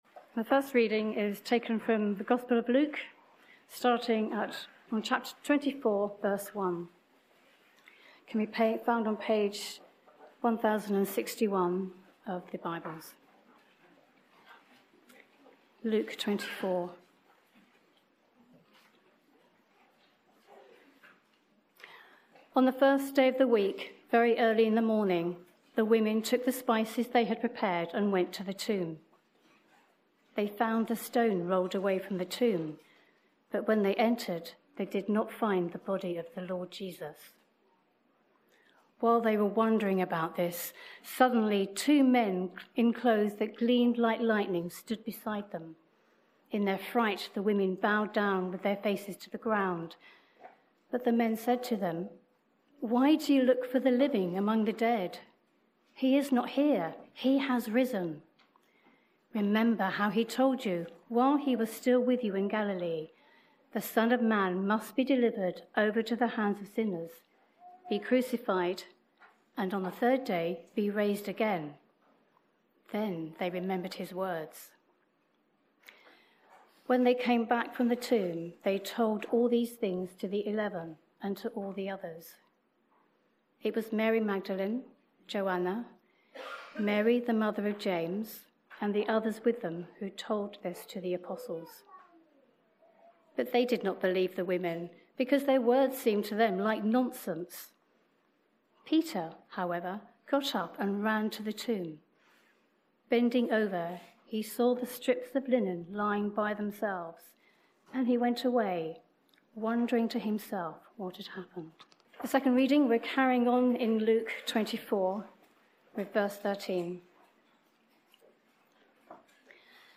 Bible Reading Part 1
Sermon Part 1